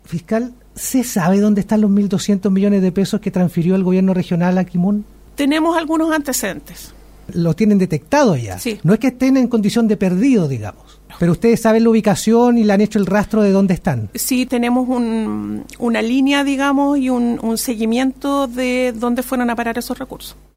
Fue en entrevista con Radio Bío Bío en Puerto Montt, a un año del anuncio de la investigación de oficio que inició el Caso Convenios en Los Lagos, que la fiscal regional Carmen Gloria Wittwer, se refirió a lo que esto ha significado para el Ministerio Público.